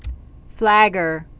(flăgər)